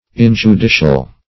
Search Result for " injudicial" : The Collaborative International Dictionary of English v.0.48: Injudicial \In`ju*di"cial\, a. Not according to the forms of law; not judicial.